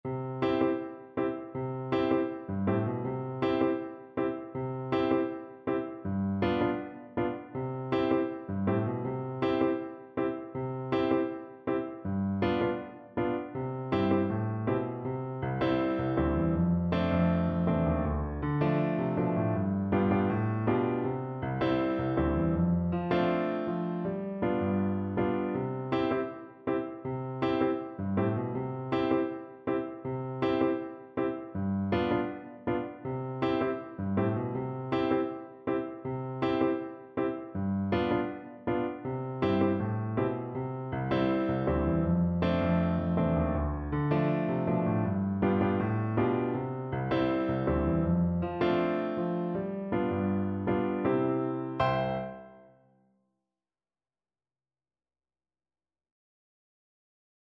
Flute version
C major (Sounding Pitch) (View more C major Music for Flute )
Fast, reggae feel =c.160 =160
4/4 (View more 4/4 Music)
Flute  (View more Easy Flute Music)
Traditional (View more Traditional Flute Music)
Caribbean Music for Flute